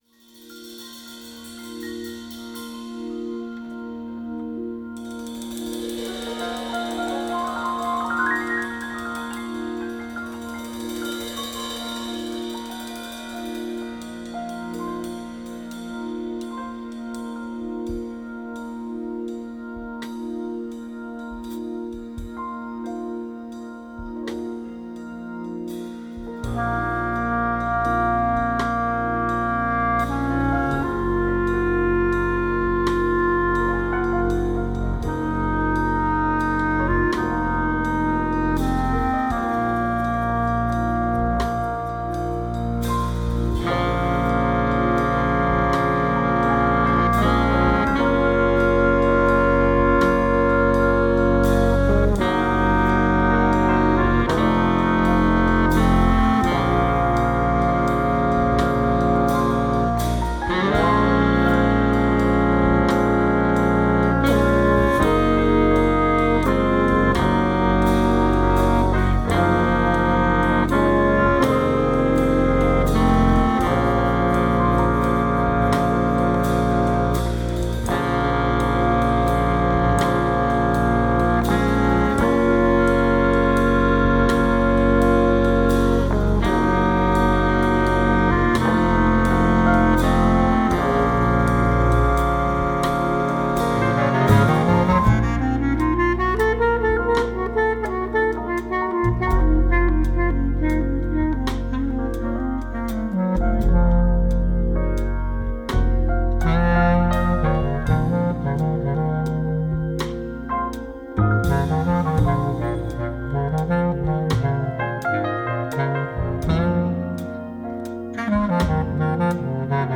bliss
Its melody is beautifully haunting.